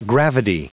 Prononciation du mot gravidae en anglais (fichier audio)
Prononciation du mot : gravidae